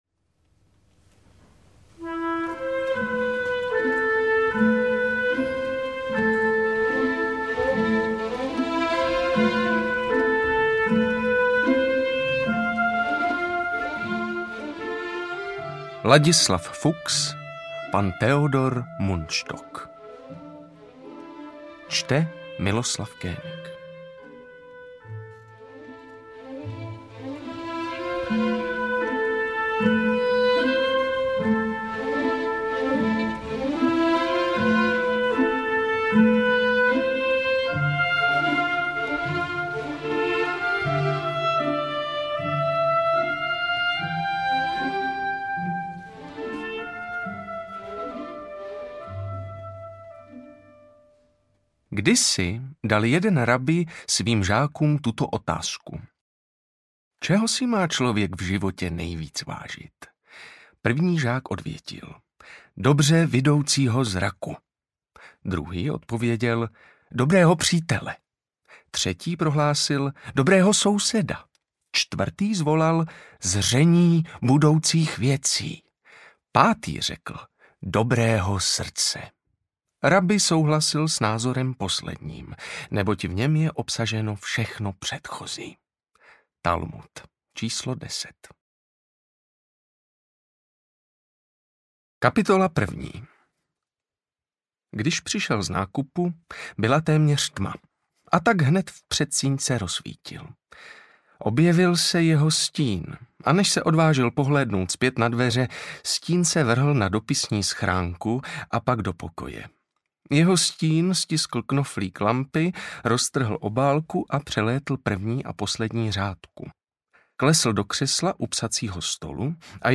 Interpret:  Miloslav König
AudioKniha ke stažení, 21 x mp3, délka 6 hod. 51 min., velikost 375,4 MB, česky